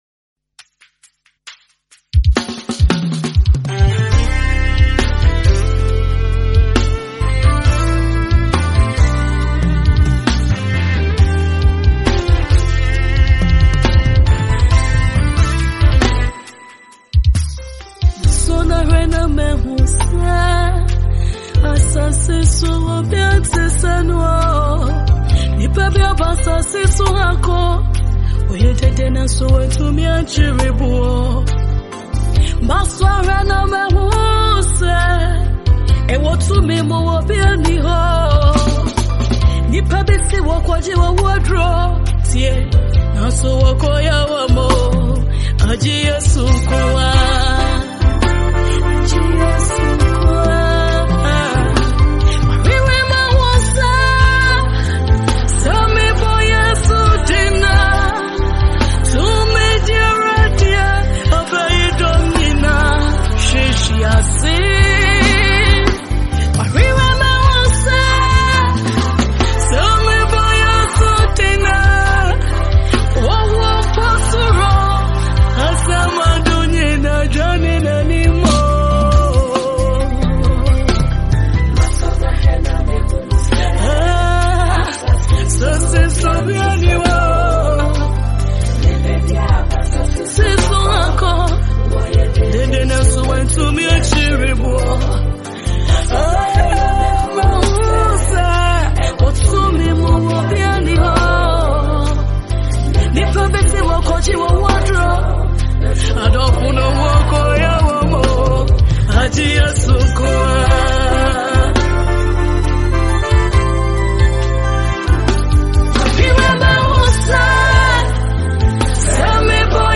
Gospel
Ghanaian female Gospel singer